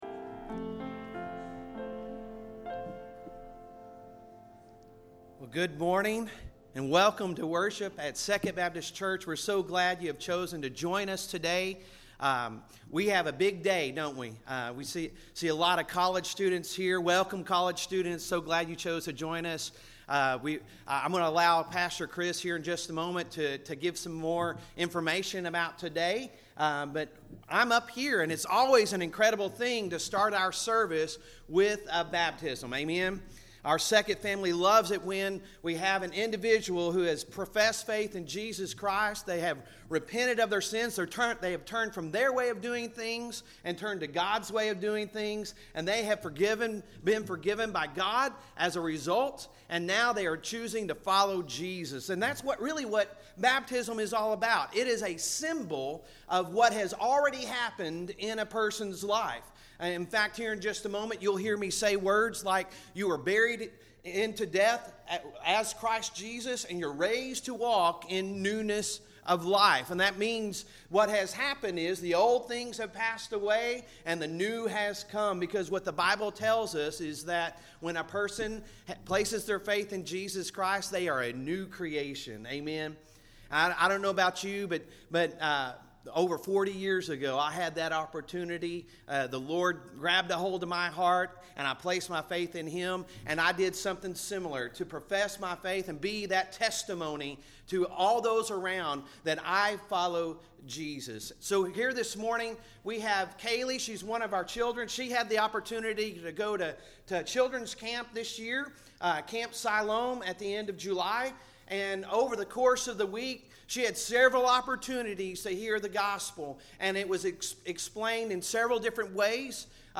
Sunday Sermon August 20, 2023